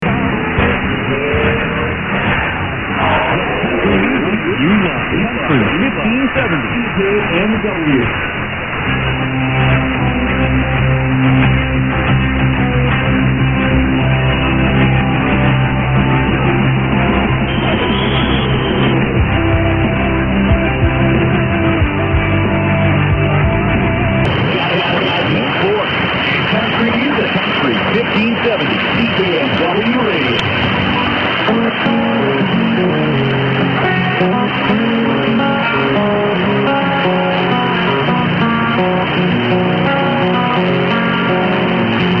A FEW AUDIO CLIPS OF RECENT RECEPTION: